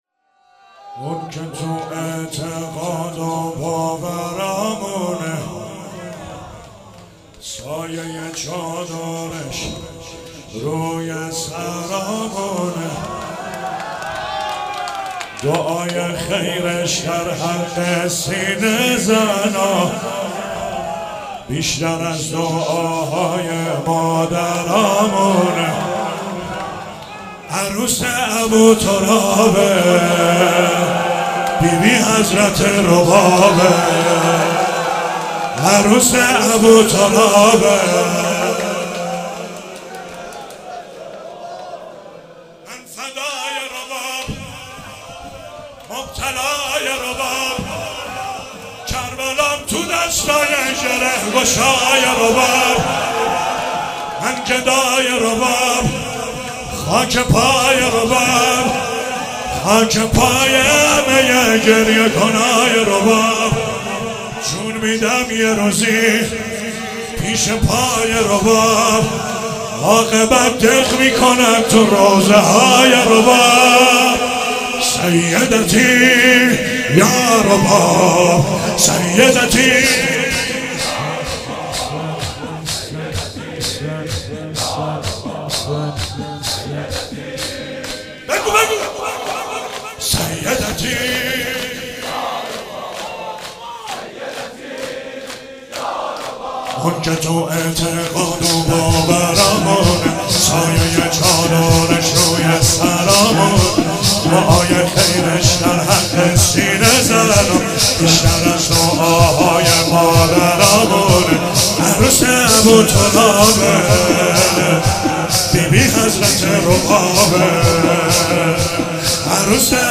مراسم هفتگی 24 فروردین 96
چهاراه شهید شیرودی حسینیه حضرت زینب (سلام الله علیها)